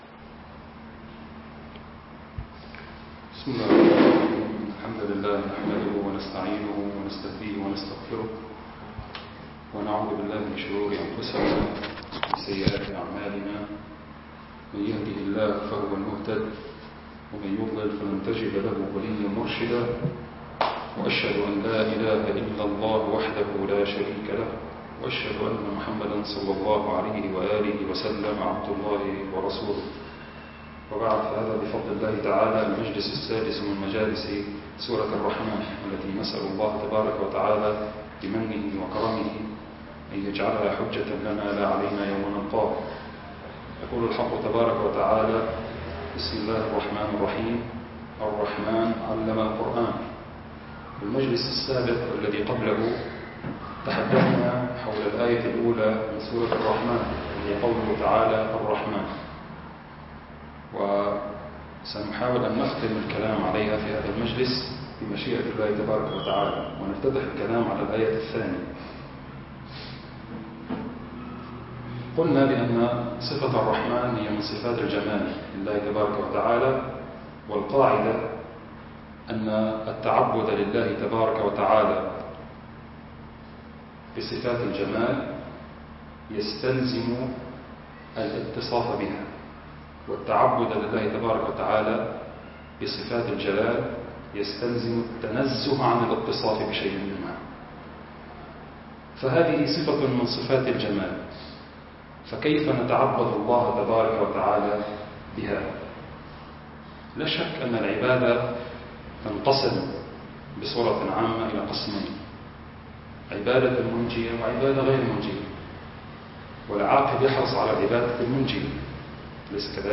المكان: مركز جماعة عباد الرحمن